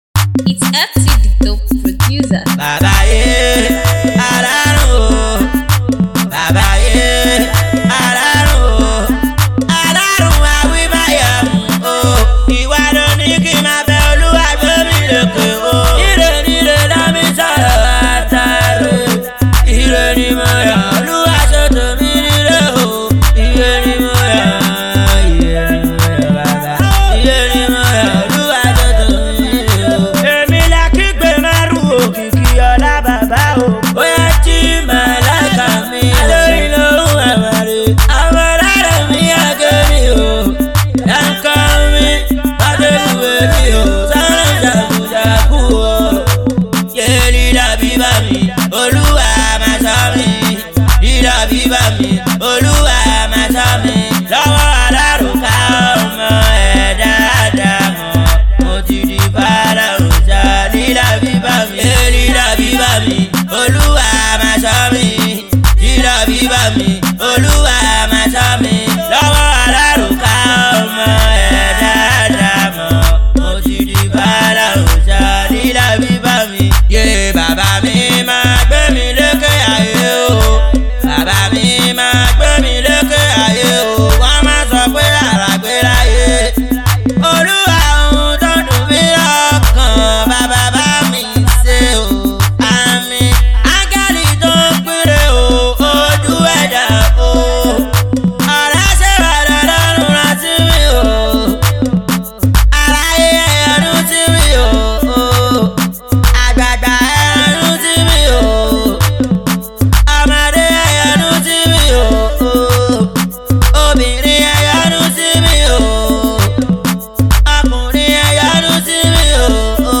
Talented Nigerian Indigenous Street Rapper/Singer